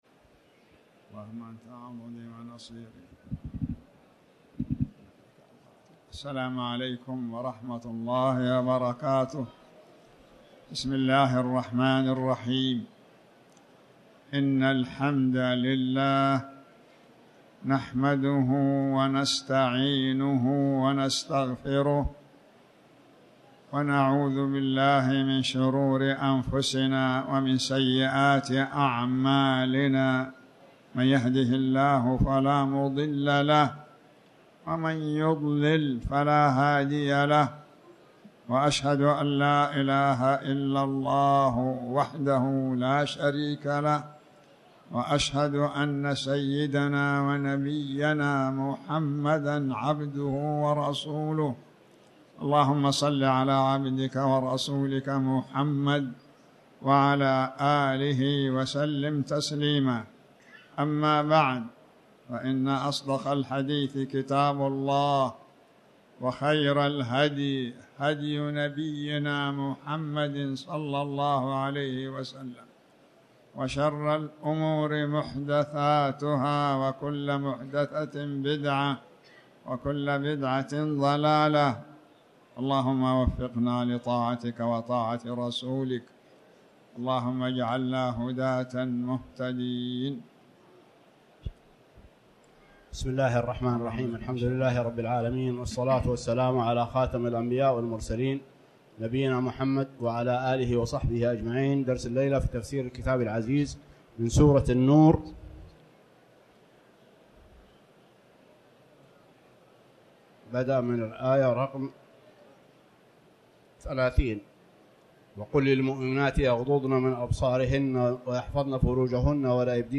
تاريخ النشر ١٢ ربيع الثاني ١٤٤٠ هـ المكان: المسجد الحرام الشيخ